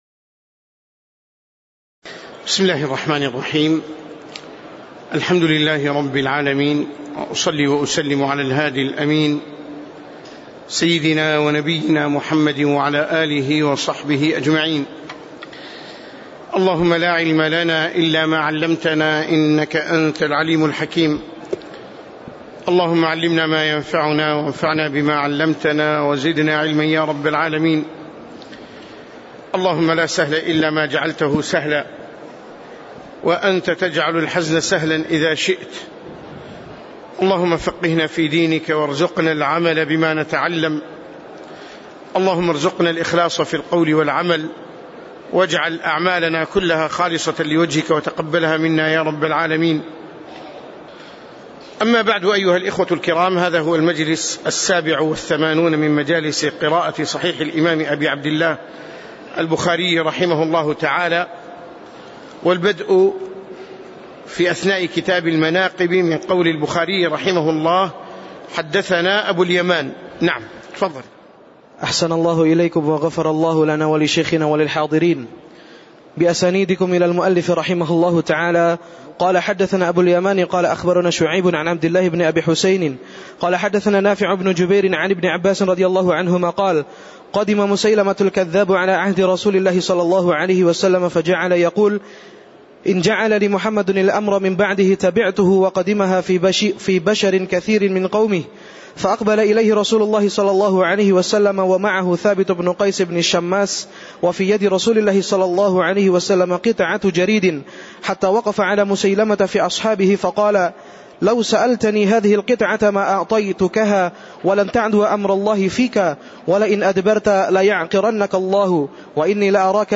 تاريخ النشر ٢٢ جمادى الآخرة ١٤٣٨ هـ المكان: المسجد النبوي الشيخ